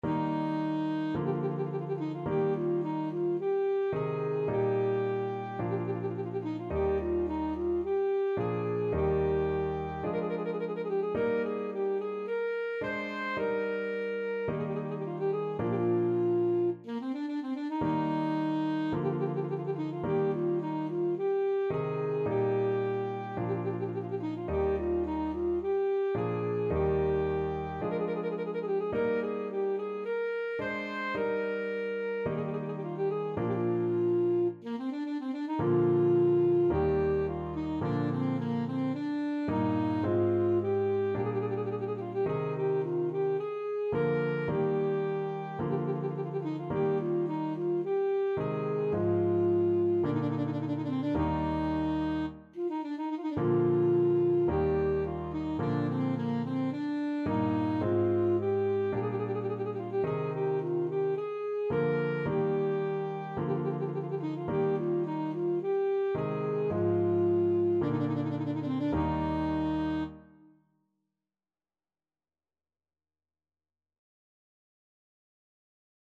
Alto Saxophone
4/4 (View more 4/4 Music)
Eb major (Sounding Pitch) C major (Alto Saxophone in Eb) (View more Eb major Music for Saxophone )
= 54 Slow
Classical (View more Classical Saxophone Music)